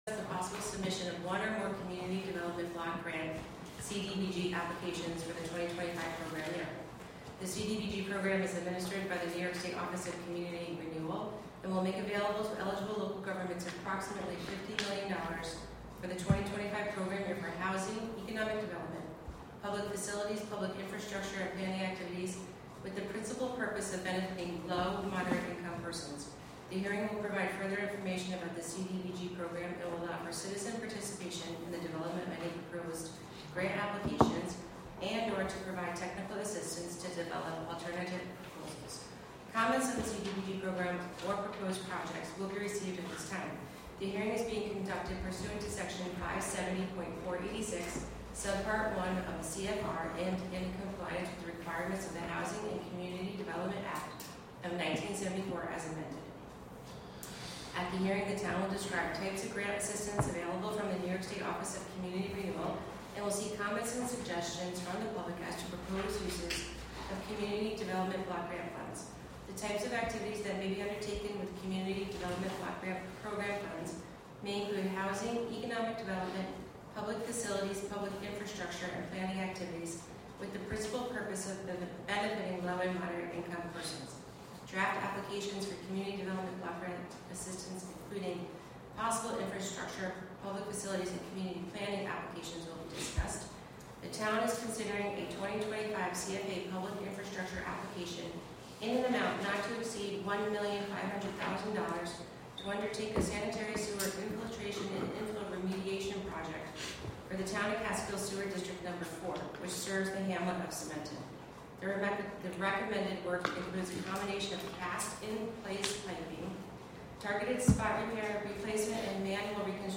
Live from the Town of Catskill: June 3, 2025 Catskill Town Board Meeting (Audio)